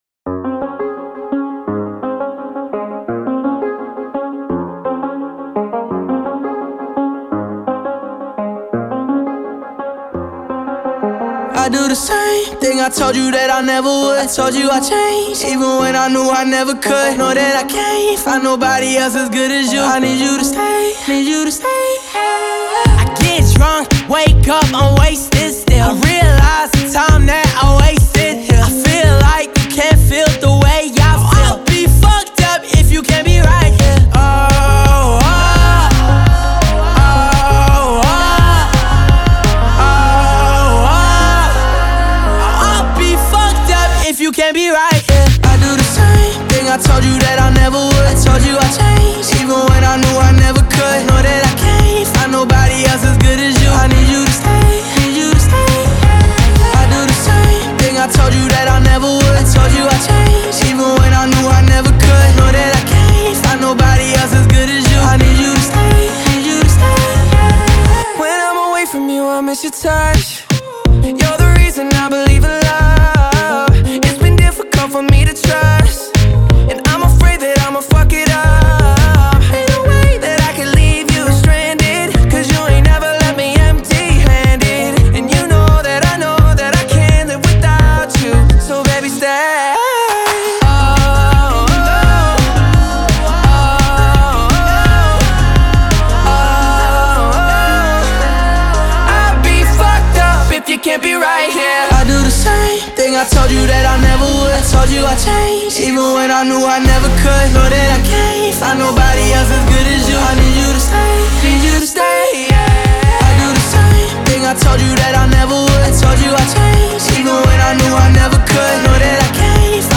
outstanding and catchy tune